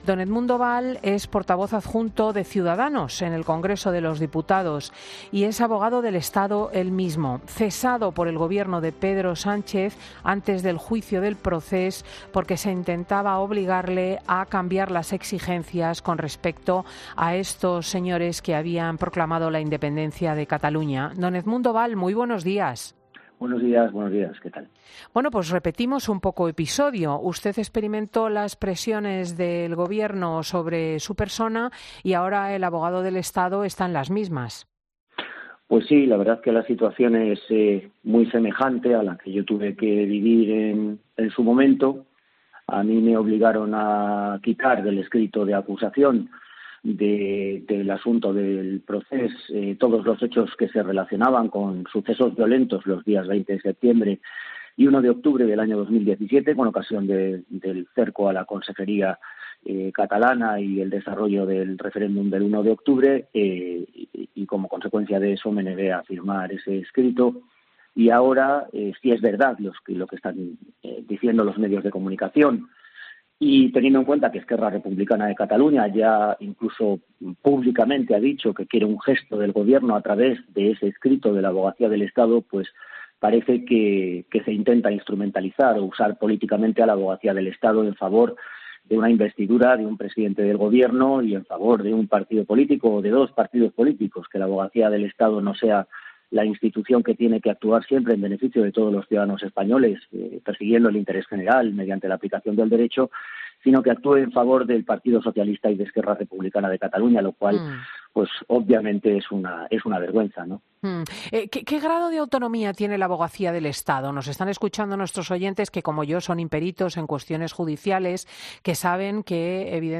El portavoz adjunto de Ciudadanos en el Congreso, Edmundo Bal en 'Fin de Semana'